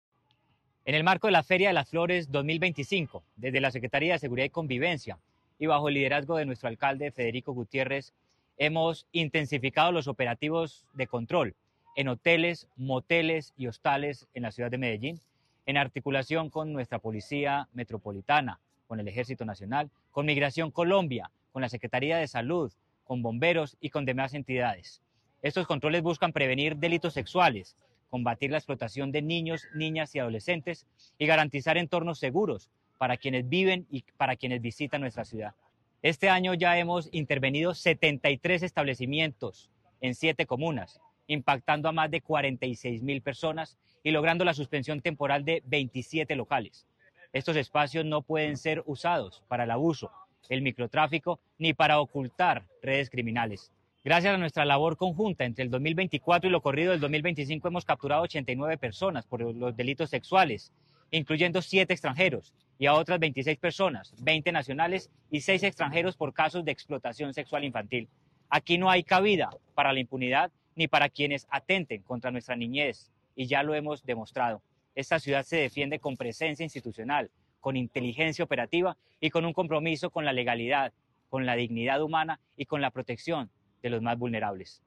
Declaraciones-secretario-de-Seguridad-y-Convivencia-Manuel-Villa-Mejia-1.mp3